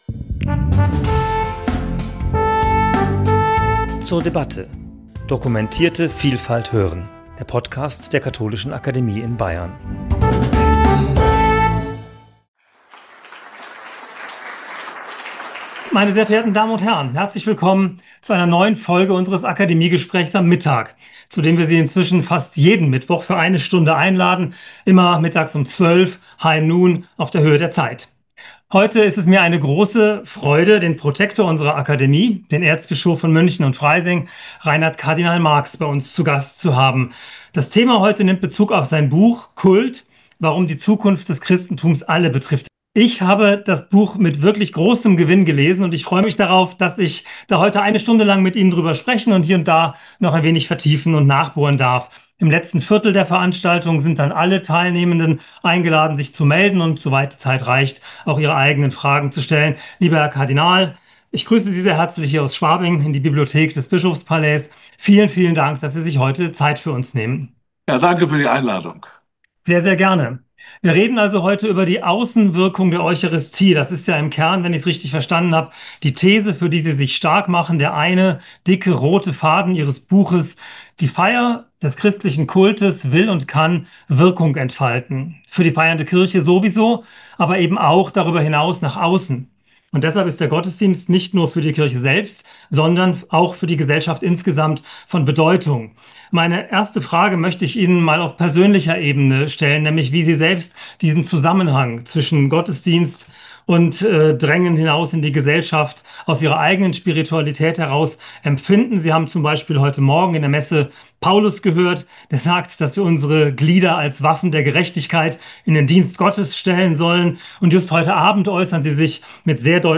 Gespräch zum Thema 'Kult & Welt – über die gesellschaftliche „Außenwirkung“ der Eucharistie' ~ zur debatte Podcast